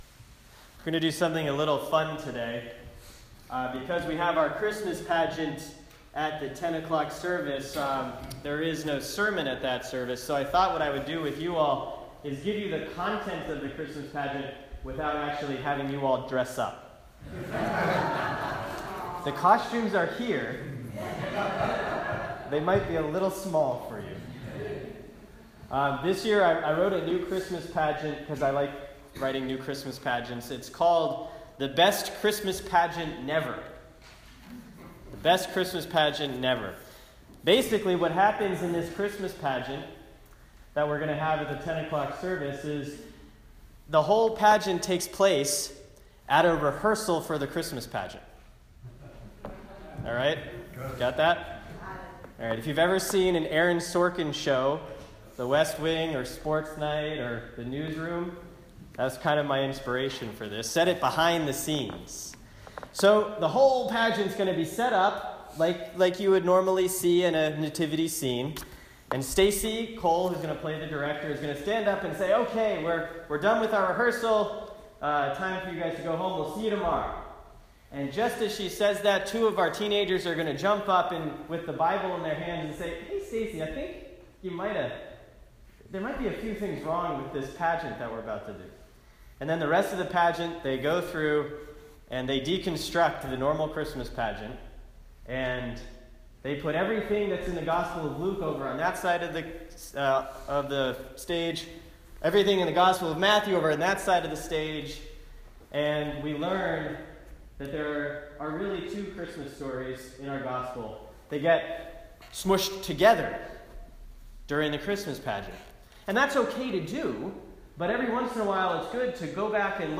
Performed at St. Mark’s in Mystic, CT on Sunday, December 18, 2016 In an homage to the preferred story-telling method of one of my writing heroes, Aaron Sorkin, this new Christmas pageant takes place during a rehearsal for a traditional Christmas pageant.